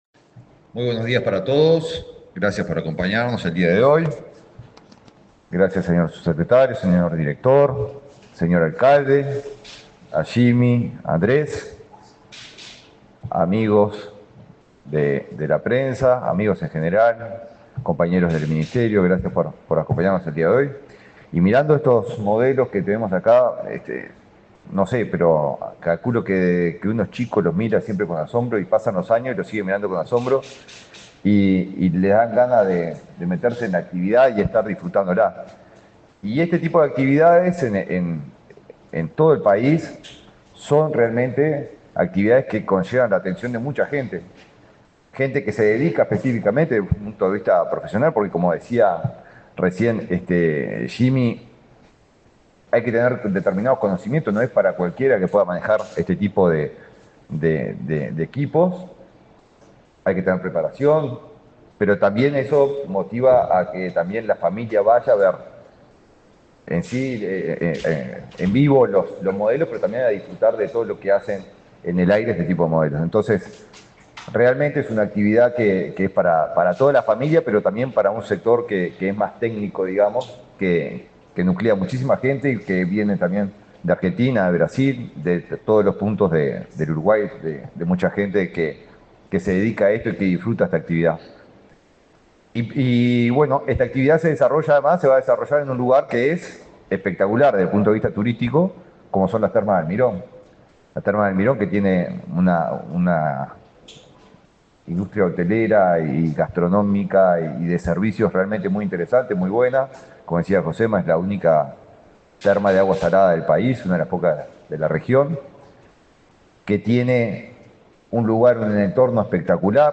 Palabras del ministro de Turismo, Eduardo Sanguinetti
El ministro de Turismo, Eduardo Sanguinetti, participó en el lanzamiento del 8.º Festival Vuela Termas, una actividad de aeromodelismo que se